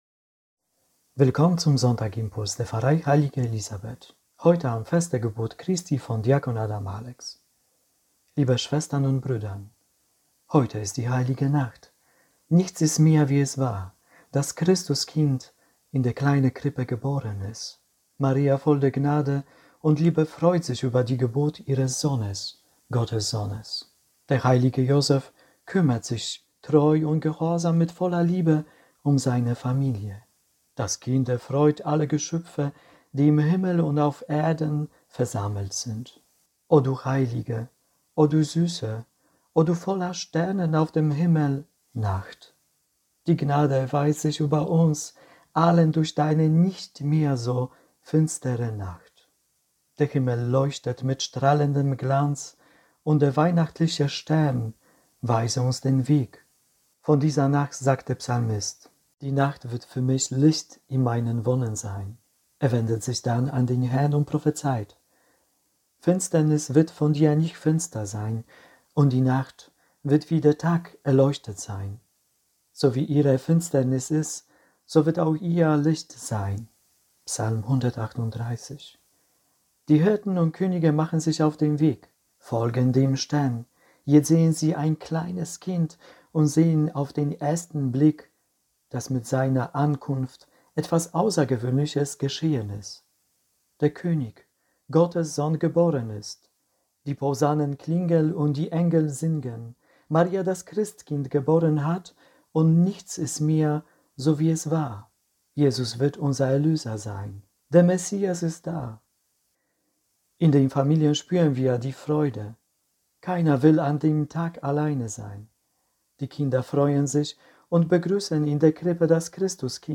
„Heute ist die Heilige Nacht, nichts ist mehr wie es war“ – Impuls zu Heiligabend